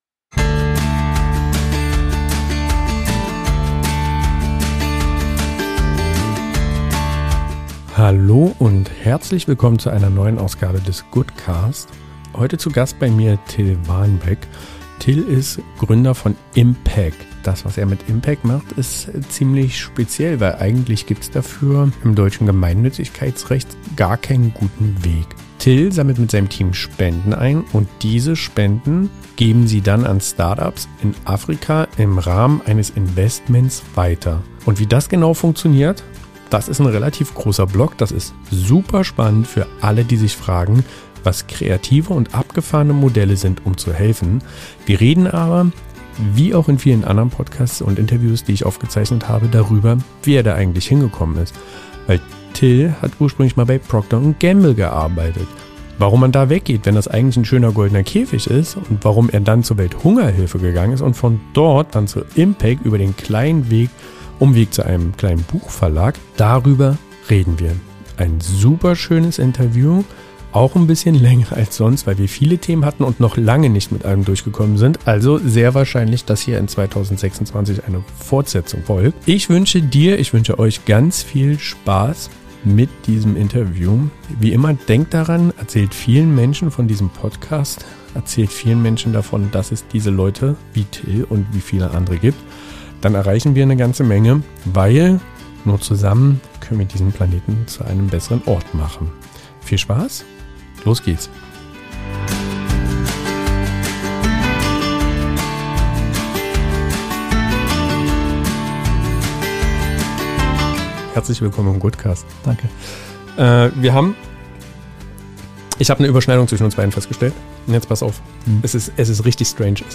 Im Gespräch teilt er, warum er den sicheren Konzernweg verlassen hat, wie man unternehmerische Mittel für gesellschaftliche Veränderungen einsetzen kann und was ihn heute antreibt. Eine inspirierende Folge über Social Entrepreneurship, Impact Investing und die Frage, wie wir mit wirtschaftlichem Denken echten sozialen Wandel schaffen können.